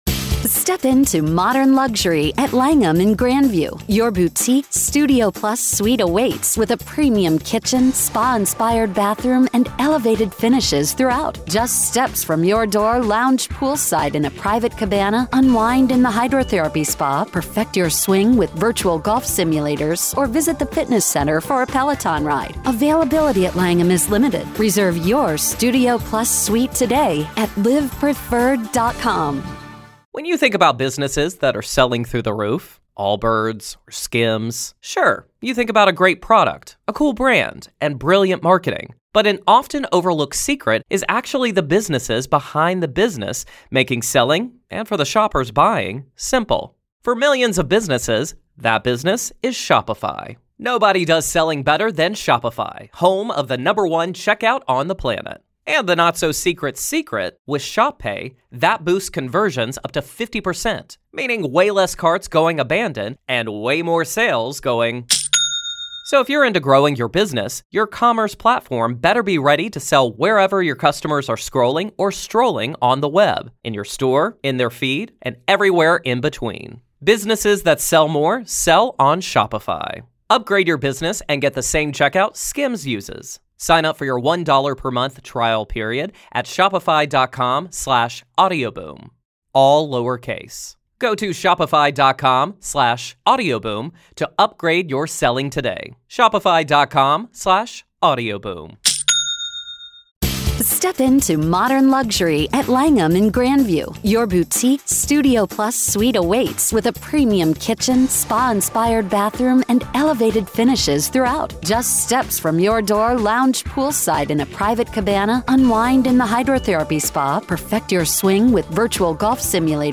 Their discussion delves into the psyche of covert narcissists and their impact in both true crime and everyday life.